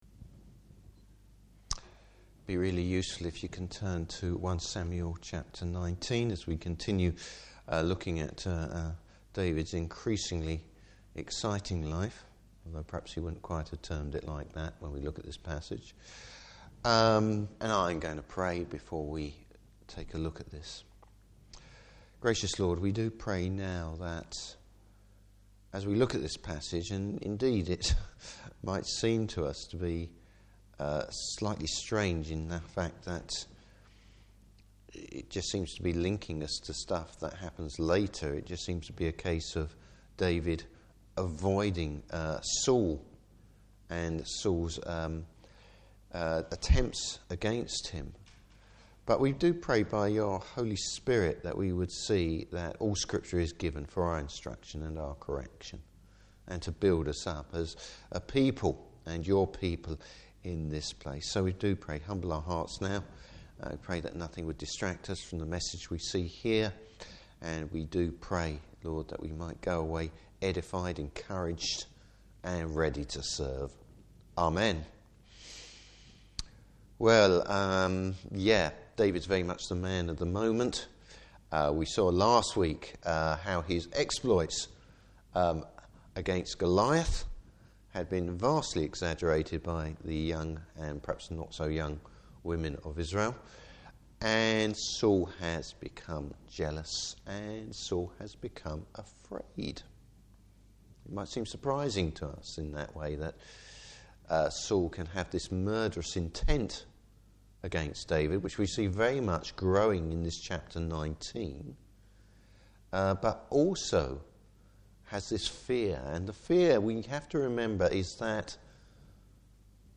Service Type: Evening Service The Lord’s protection.